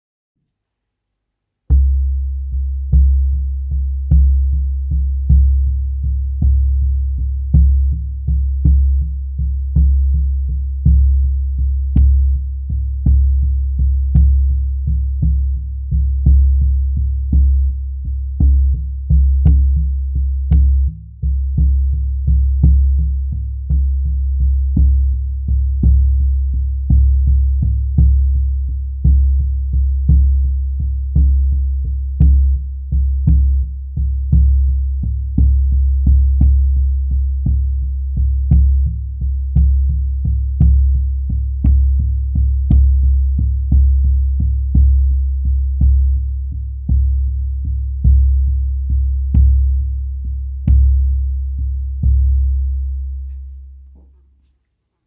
Kameel Drum
Kamelendrum heeft een diepe klank.
De drum is 47 cm doorsnee en het frame is van es.
Drumstok van eik en een wolventand.
Dit is een zwaardere drum.